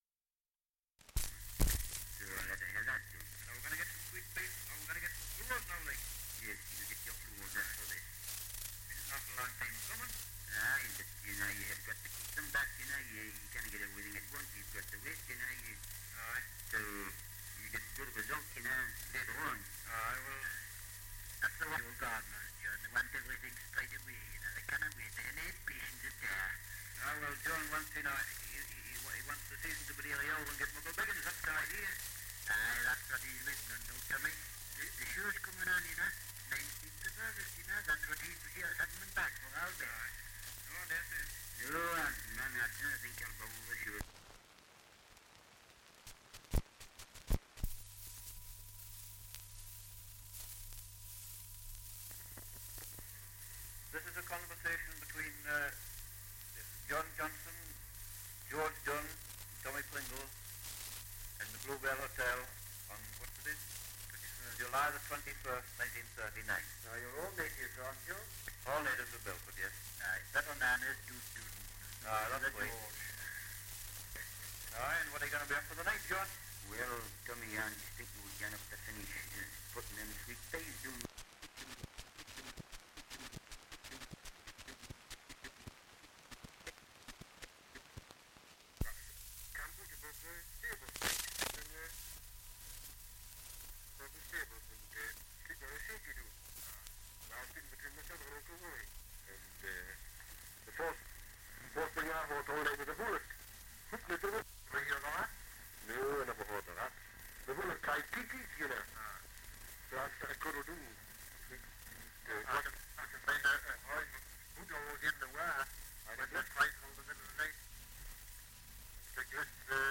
Dialect recording in Belford, Northumberland
78 r.p.m., cellulose nitrate on aluminium